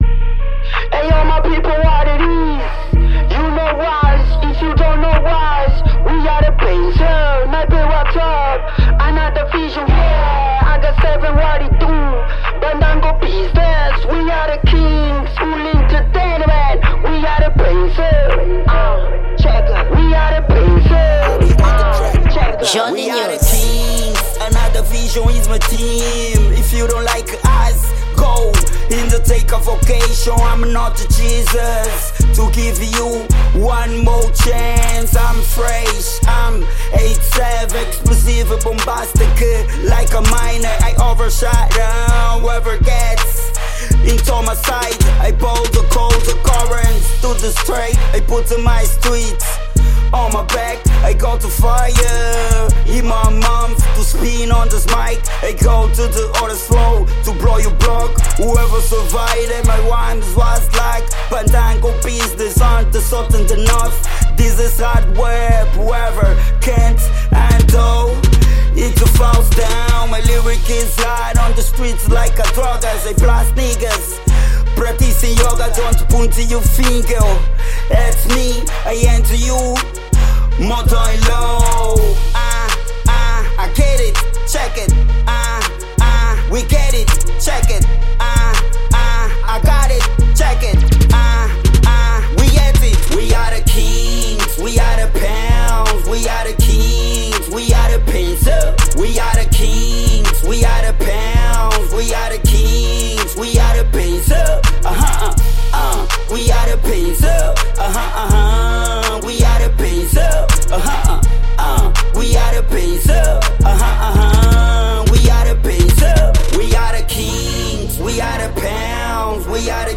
Rap com atitude e confiança.
Gênero: Rap